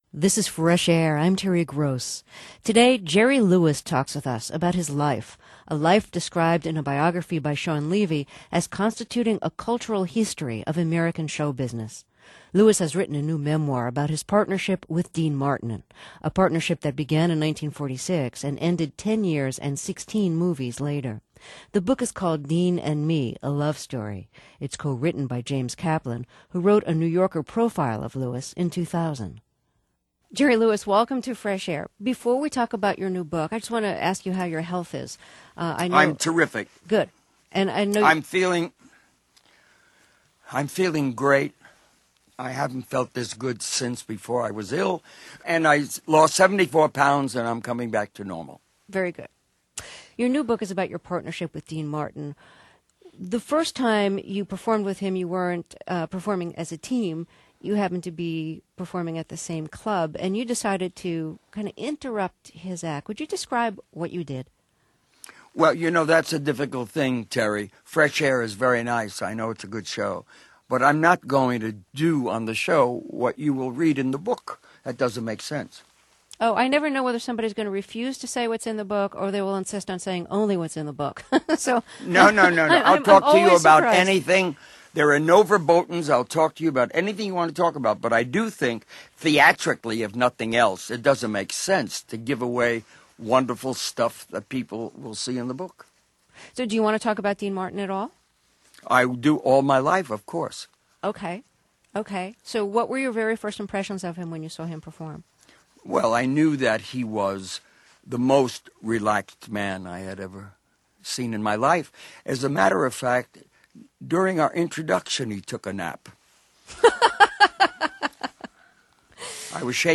Jerry Lewis – Interview with Terry Gross – NPR: Fresh Air – October 25, 2005 – National Public Radio